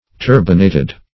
Turbinate \Tur"bi*nate\, Turbinated \Tur"bi*na`ted\, a. [L.